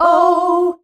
OUUH  F.wav